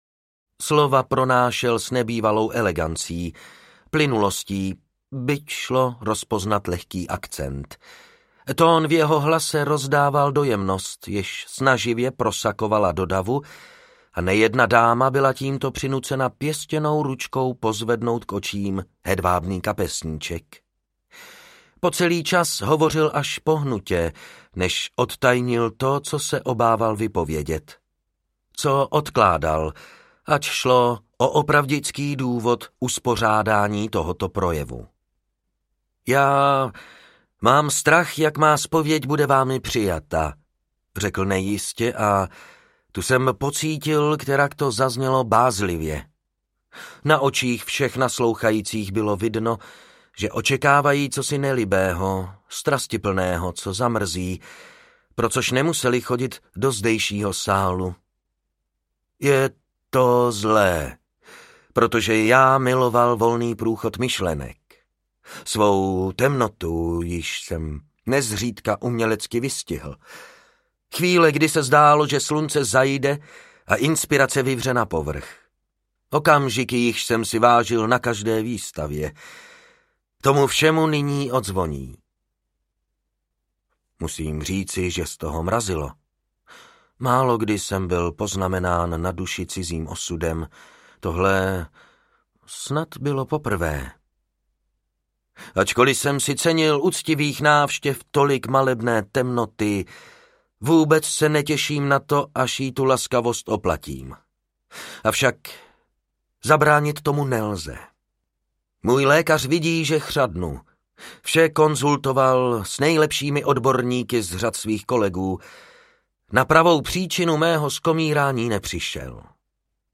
RIP: Smrt je malebná audiokniha
Usaďte se, prosím, do křesel a naslouchejte mému temnému hlasu.
Ukázka z knihy
Vyrobilo studio Soundguru.